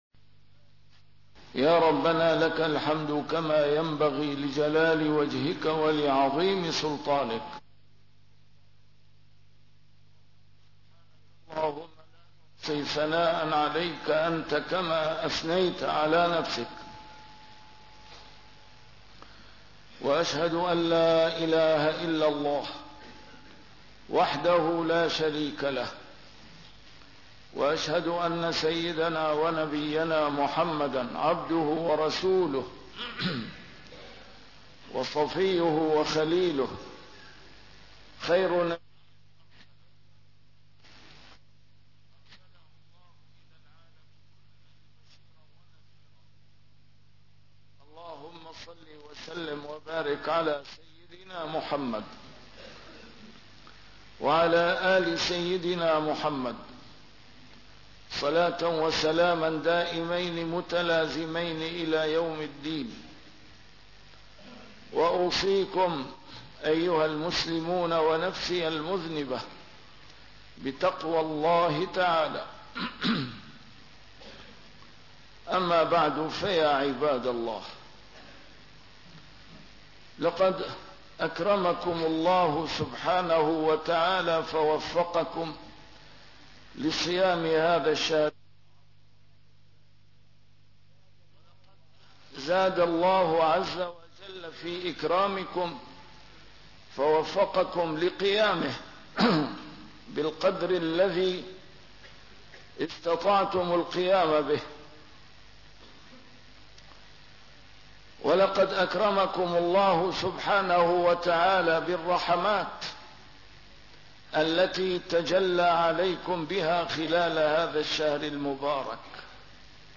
A MARTYR SCHOLAR: IMAM MUHAMMAD SAEED RAMADAN AL-BOUTI - الخطب - واجب التوبة والإنابة إلى الله سبحانه وتعالى